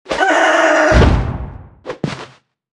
音效
Media:anim_dragonchicken_noroar_01.wav 动作音效 anim 查看其技能时触发动作的音效
Anim_dragonchicken_noroar_01.wav